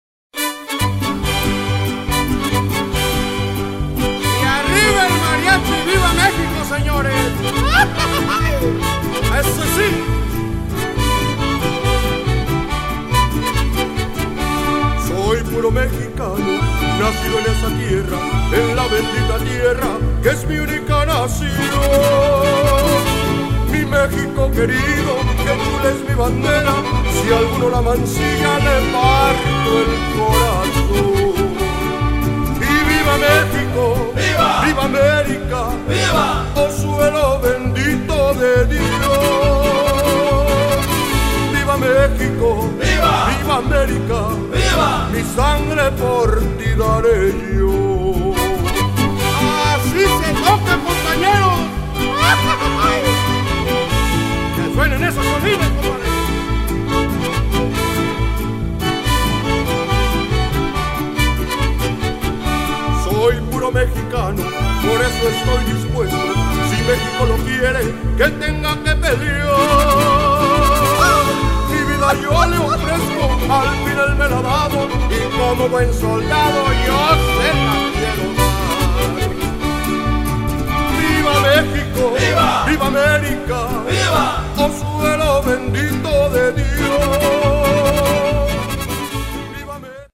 They are comprised of 10 musicians of diverse cultures.